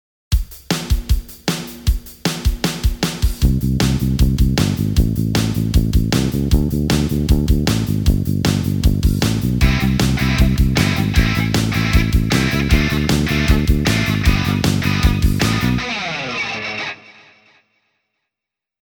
ロックのベースパターン
8ビートのベーシックなロックのベースラインです。コード進行はC→Am→Dm→G。
Cメジャーキーで作られたこのコード進行のスケールからは外れているのですが、経過音として、次の小節への繋ぎに使っています。
bass-8beat-rock.mp3